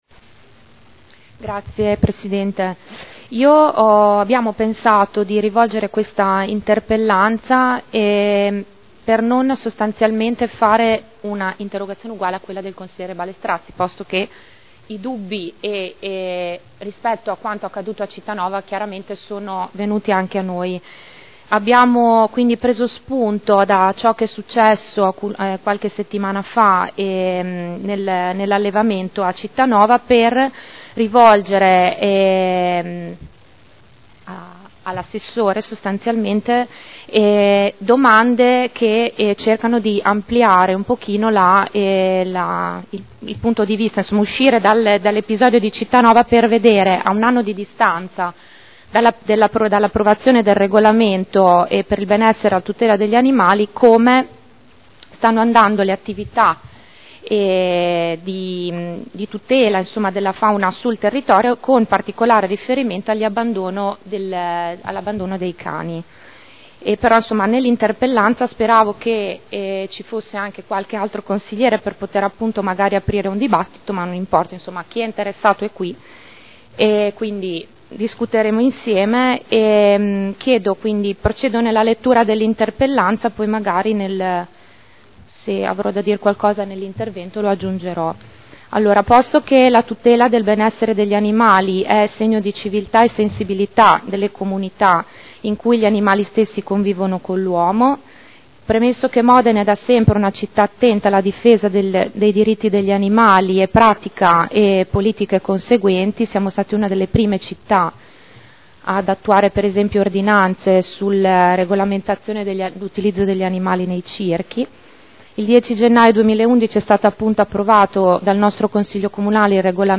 Elisa Sala — Sito Audio Consiglio Comunale